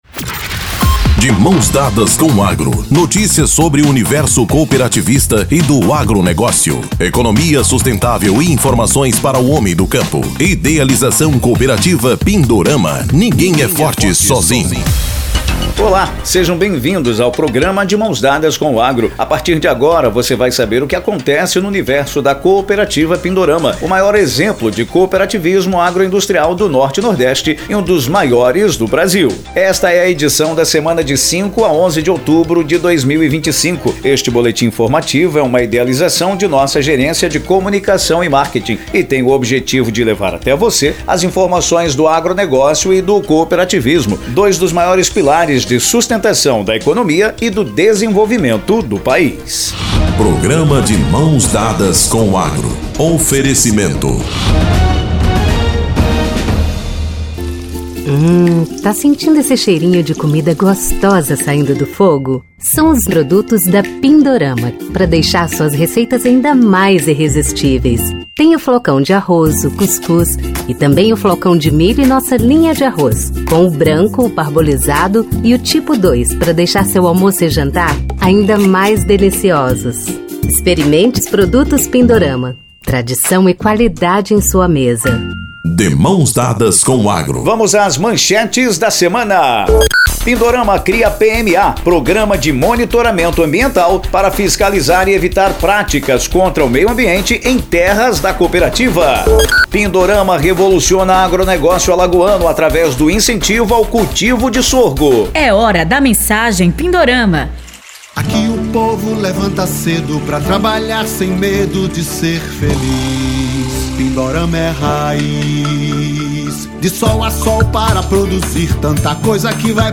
Boletim informativo da cooperativa destaca ainda o pioneirismo no cultivo de sorgo para produção de etanol em Alagoas